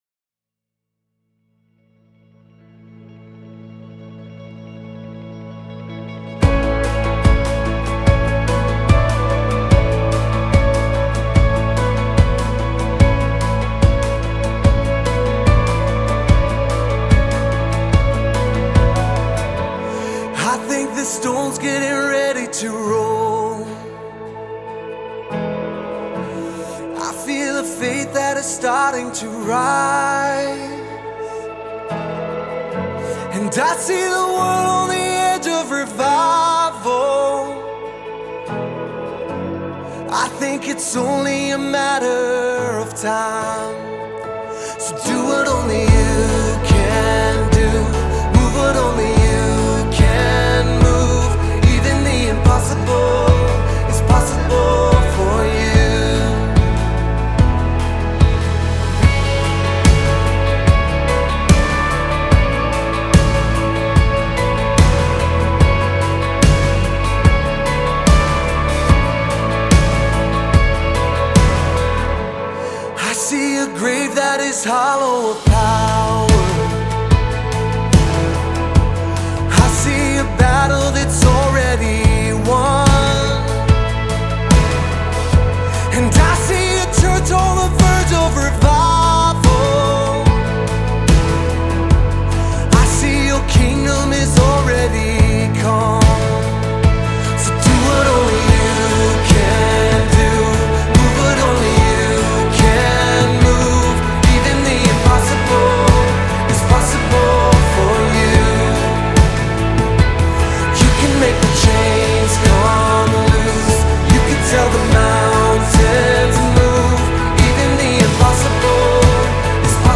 451 просмотр 215 прослушиваний 43 скачивания BPM: 73